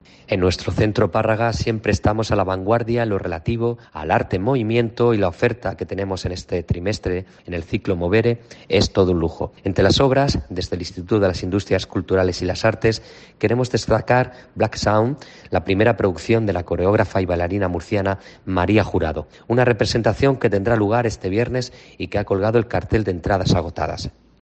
Manuel Cebrián, director general del ICA